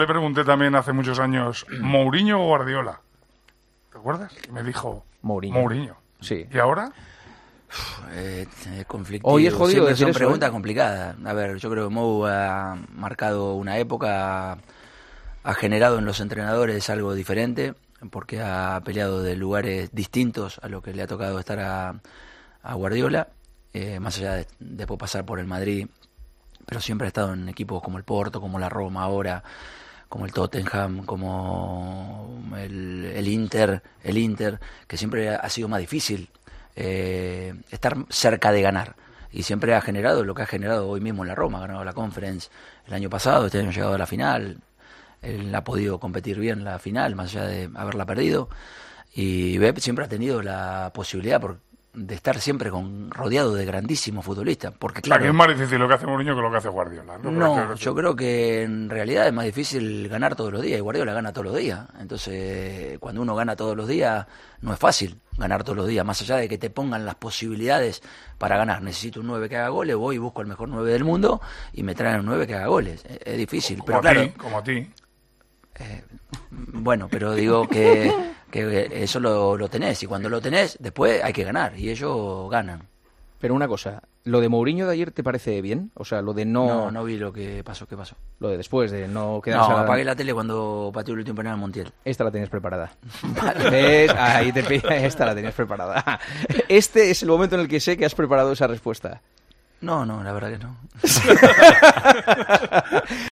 El entrenador del Atlético de Madrid fue preguntado en su entrevista en 'El Partidazo de COPE' sobre lo que hizo con la reacción del portugués tras perder: "La tenías preparada".